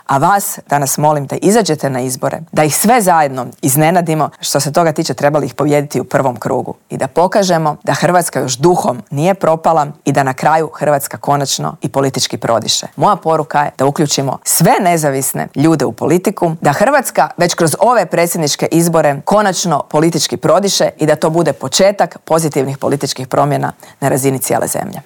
U studiju Media servisa svoj izborni program predstavila nam je nezavisna kandidatkinja Marija Selak Raspudić: "Nismo osuđeni na sukob Milanovića i Plenkovića, većina ljudi samo želi normalne ljude na čelu države."